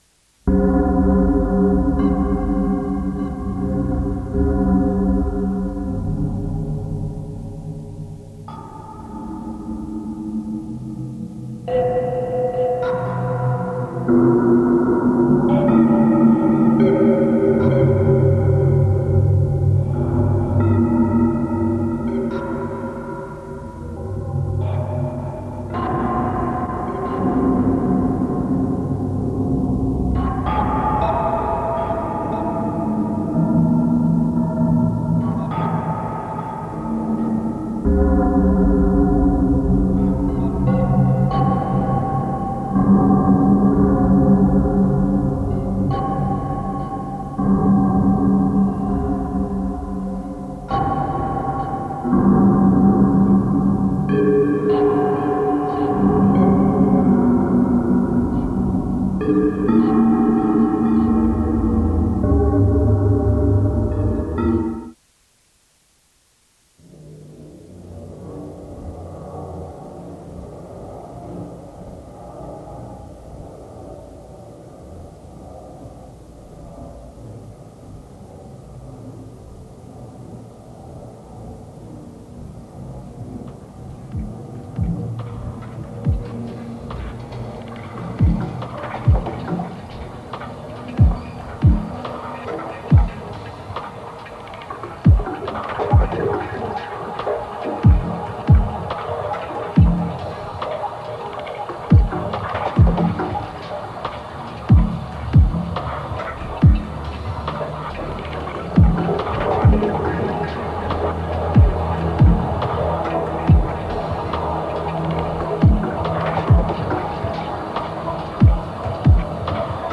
28.12.2024 Genre: Dark Ambient